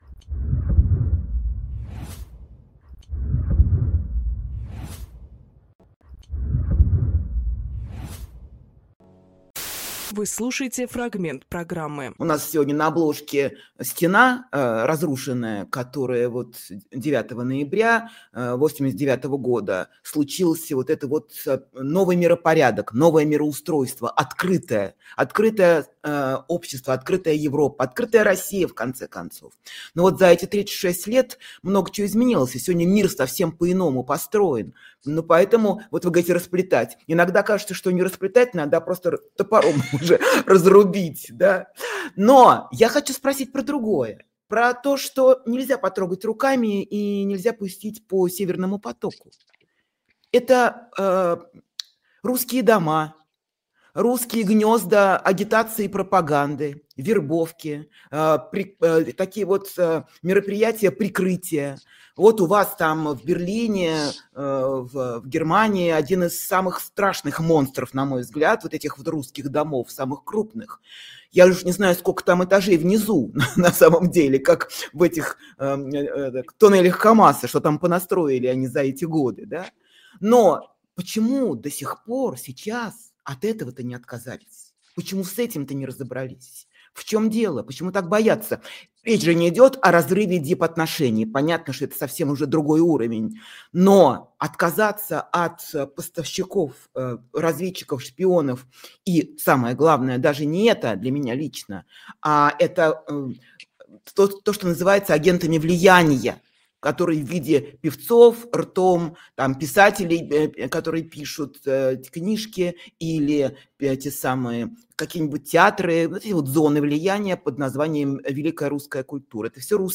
Сергей Лагодинскийдепутат Европарламента
Фрагмент эфира от 10.11.25